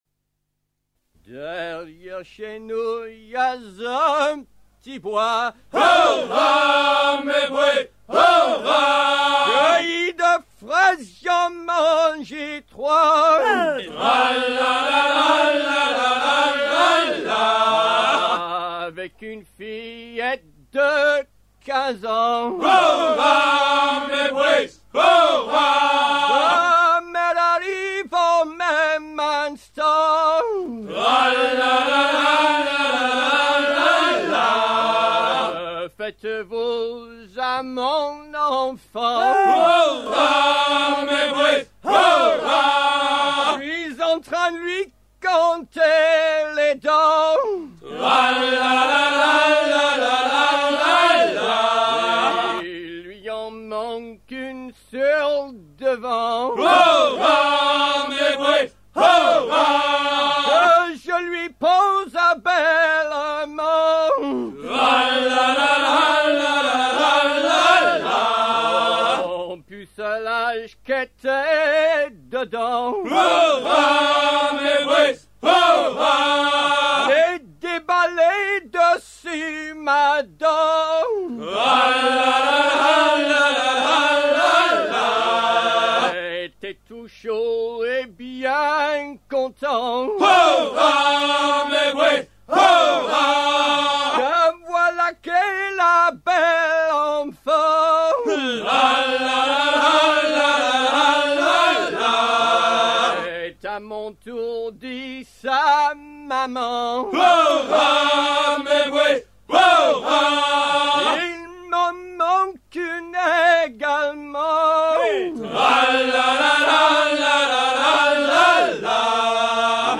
Fonction d'après l'analyste gestuel : à hisser main sur main ;
Genre laisse